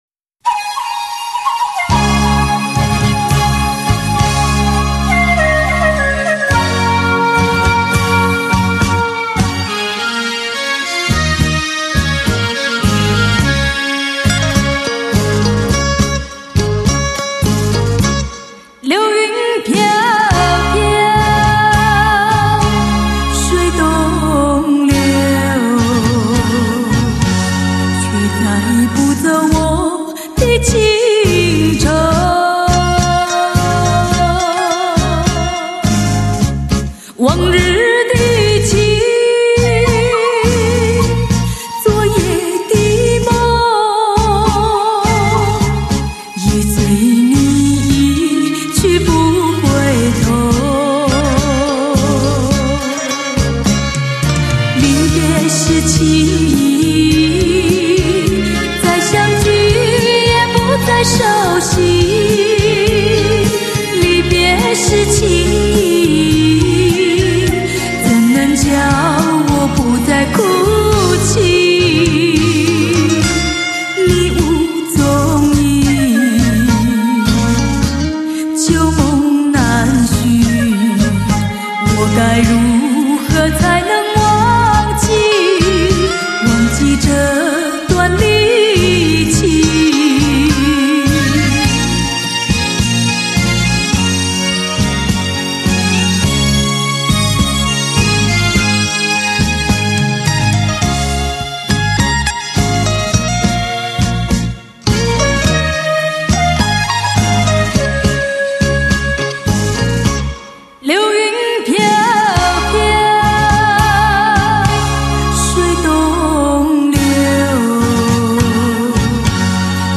她用温柔的声音